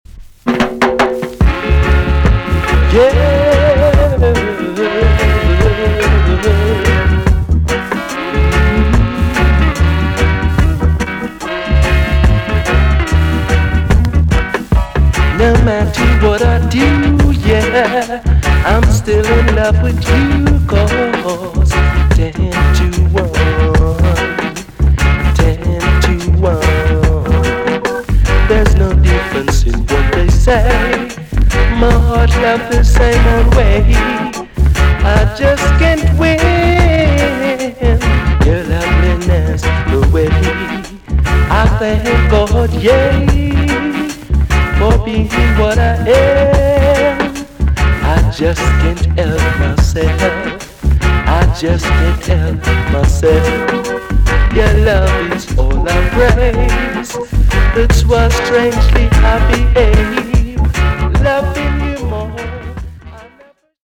TOP >REGGAE & ROOTS
EX-~VG+ 少し軽いヒスノイズが入ります。